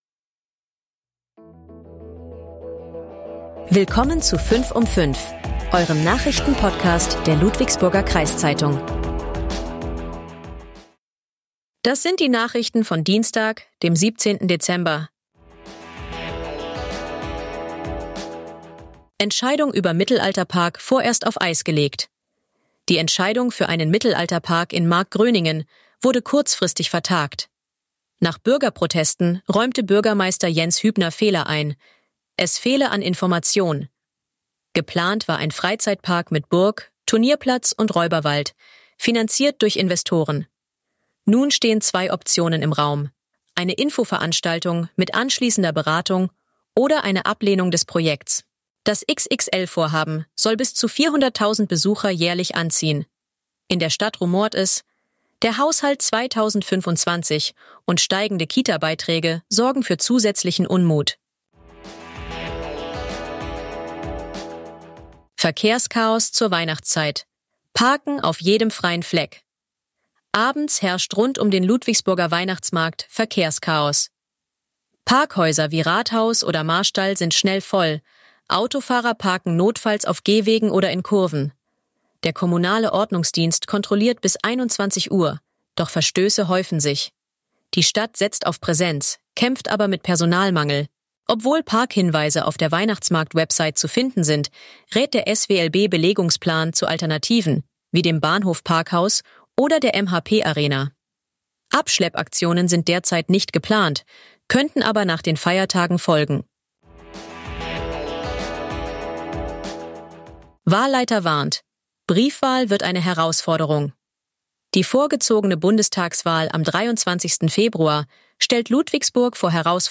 Euer Nachrichten-Podcast der Ludwigsburger Kreiszeitung